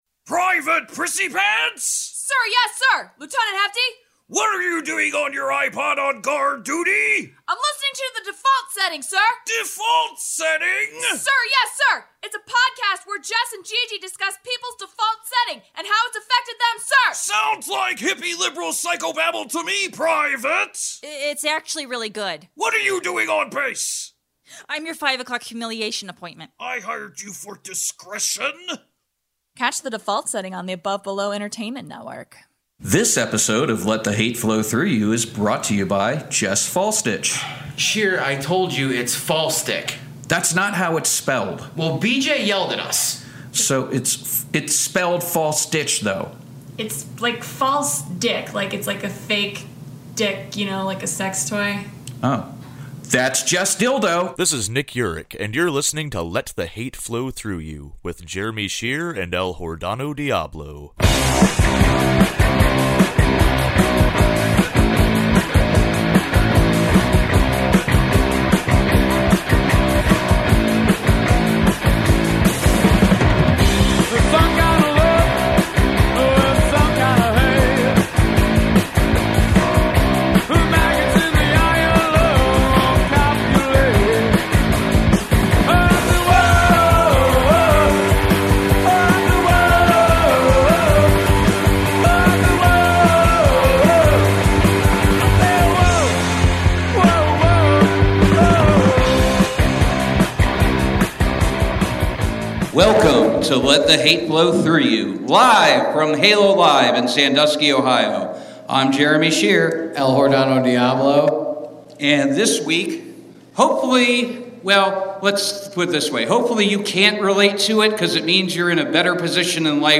Recorded live at Halo Live in Sandusky, OH.